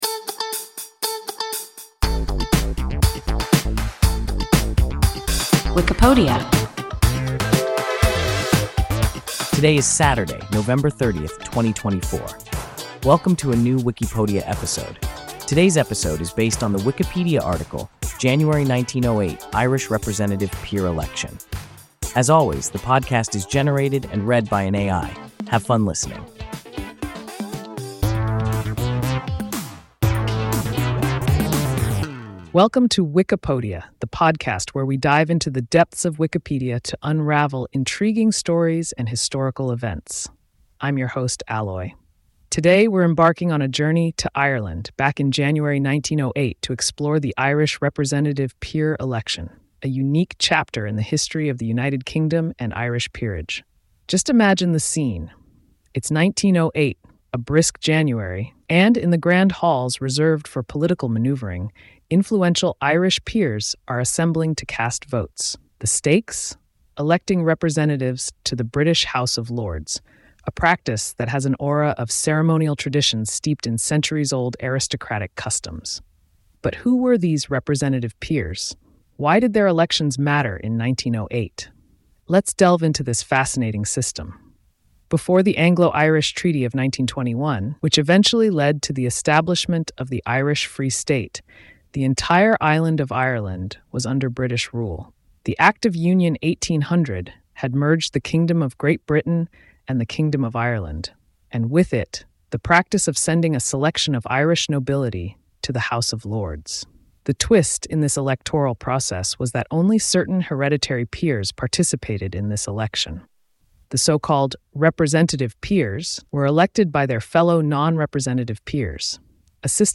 January 1908 Irish representative peer election – WIKIPODIA – ein KI Podcast